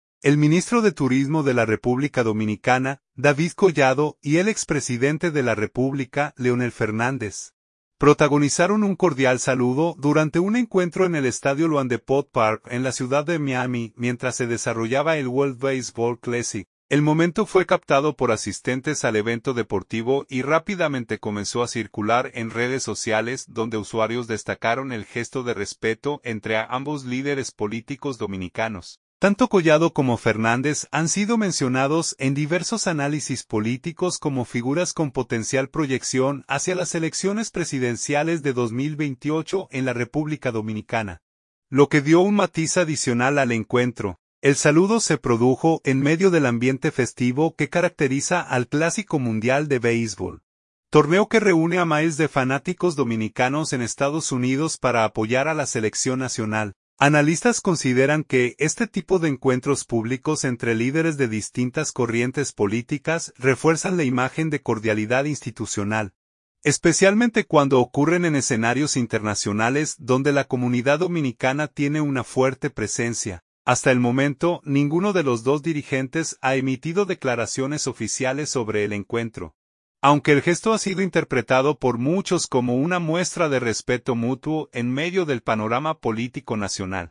El momento fue captado por asistentes al evento deportivo y rápidamente comenzó a circular en redes sociales, donde usuarios destacaron el gesto de respeto entre ambos líderes políticos dominicanos.
El saludo se produjo en medio del ambiente festivo que caracteriza al Clásico Mundial de Béisbol, torneo que reúne a miles de fanáticos dominicanos en Estados Unidos para apoyar a la selección nacional.